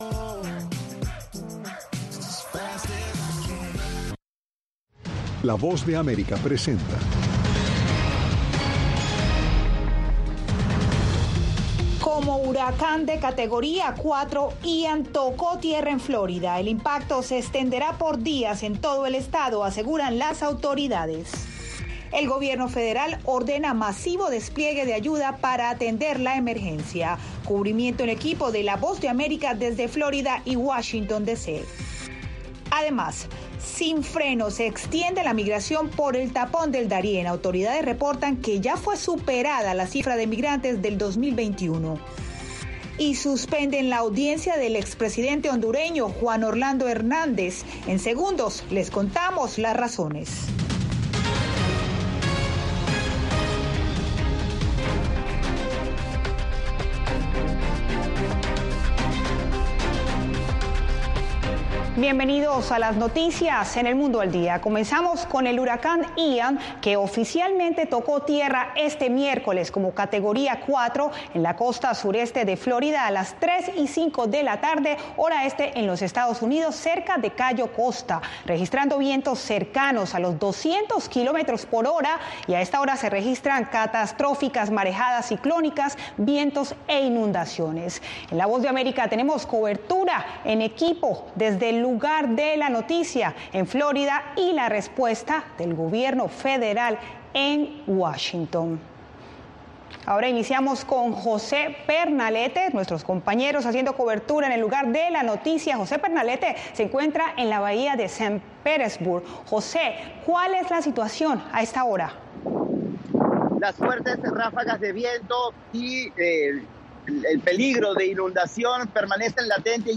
El gobierno federal ordena masivo despliegue de ayuda para atender la emergencia. Cubrimiento en equipo de la Voz de América desde Florida y Washington D.C.